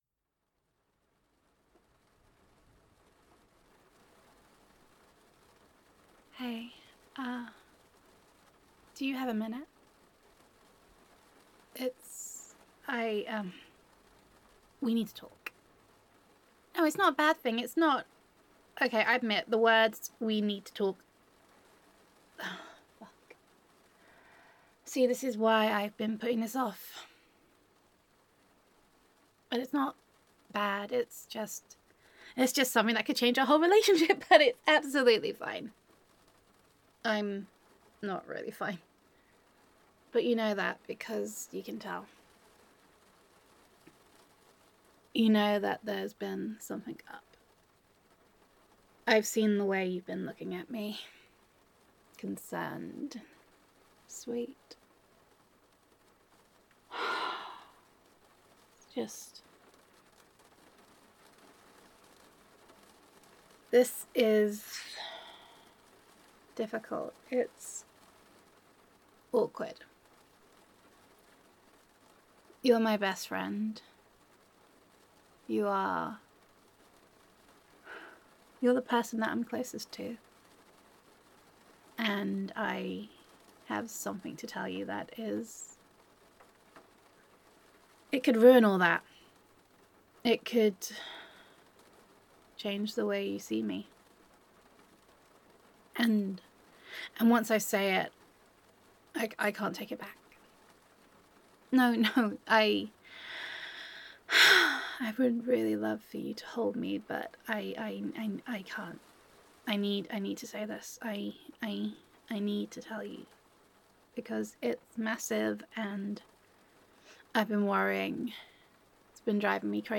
[F4A] Well That Takes the Biscuit [We Need to Talk][Confusion][Biscuit Betrayal][Gender Neutral][Friends to Lovers][Adorkable Love Confession Roleplay]